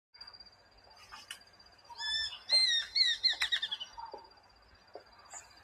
松雀鹰叫声